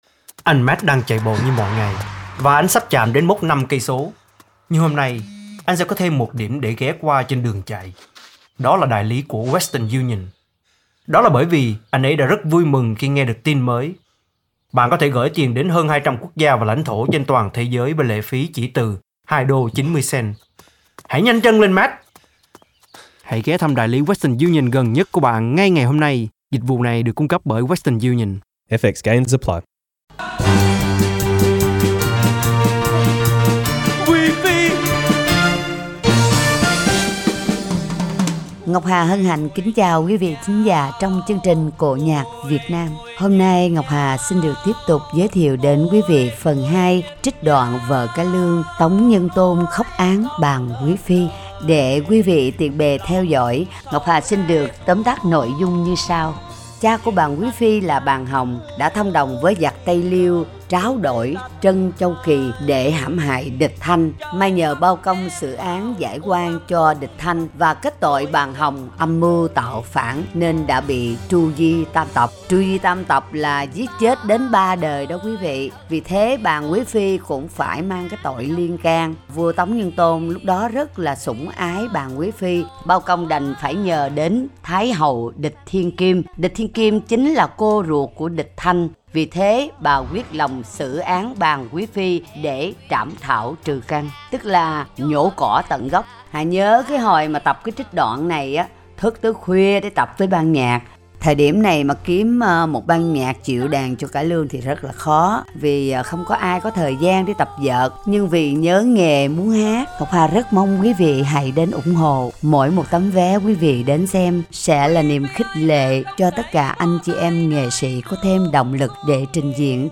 Giọng Ca Vọng Cổ: Tuồng cải lương Xử án Bàng Quý Phi